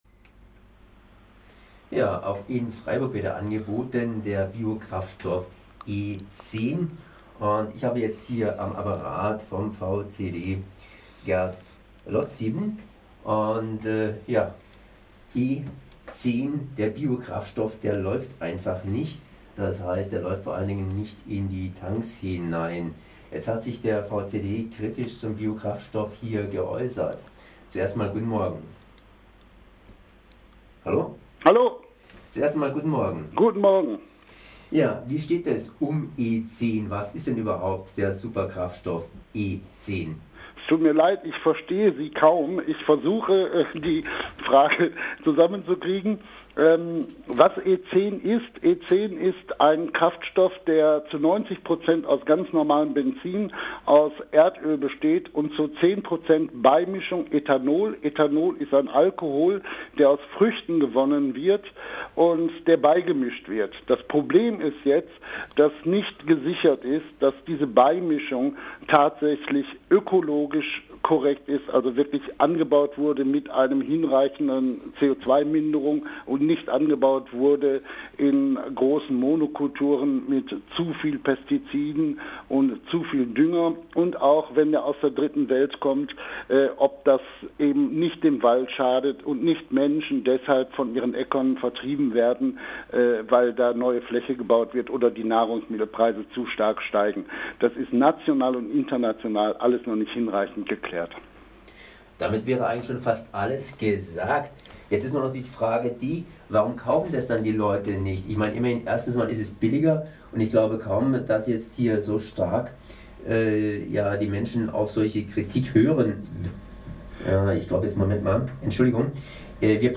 (Schlechte Tonqualität)Der Kraftstoffzusatz E10 ist unbeliebt. Die Tankstellen bleiben auf ihm sitzen.